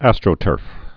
(ăstrō-tûrf)